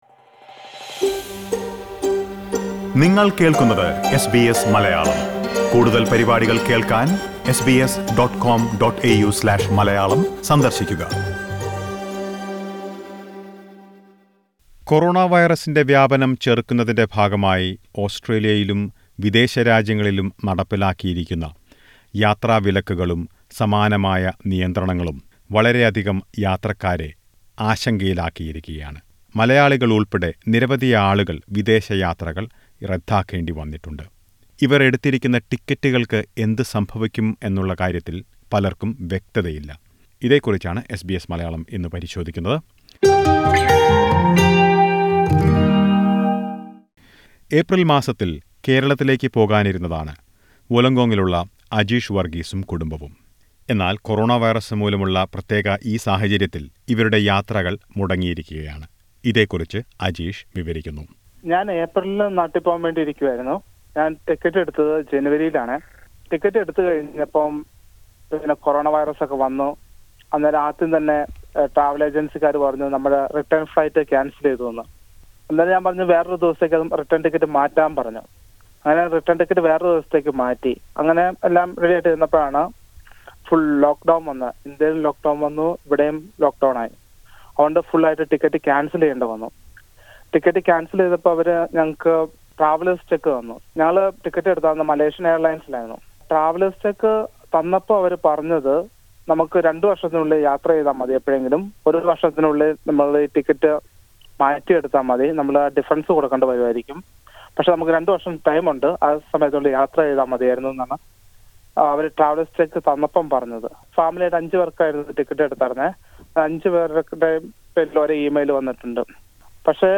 Due to corona virus restrictions many airline tickets are cancelled and passengers are worried that they will lose their money. Listen to a report.